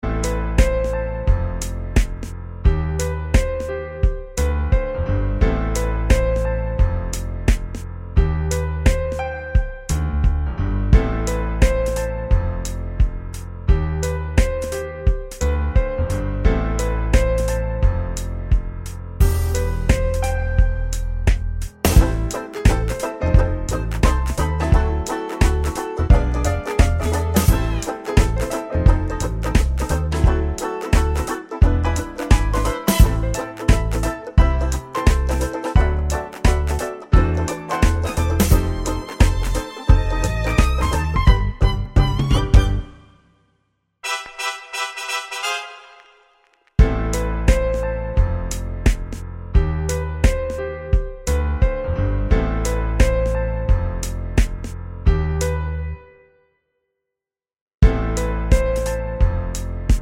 no Backing Vocals Soundtracks 4:32 Buy £1.50